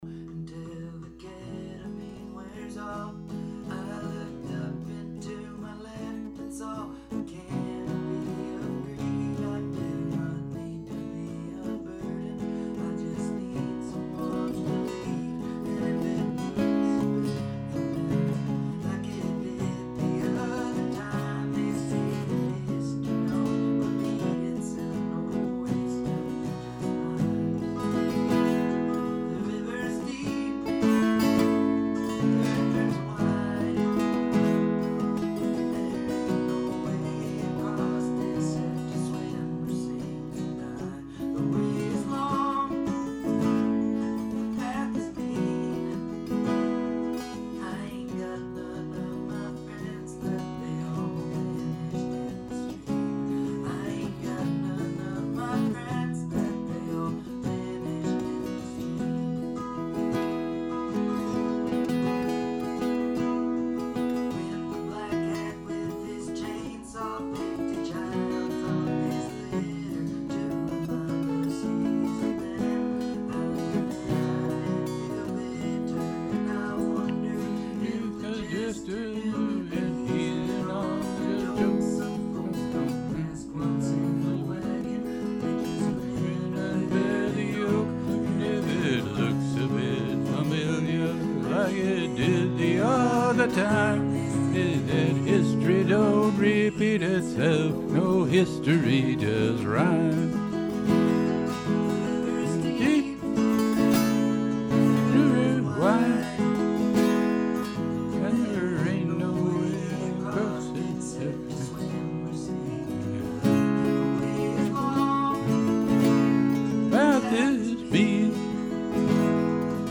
Very rough working on strum and vocal